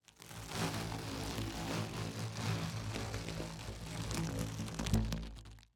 Tubular,PVCpipe,10ftL,1inDia,plasticdrawstring,pulling,crumpling,crispy,open,2.ogg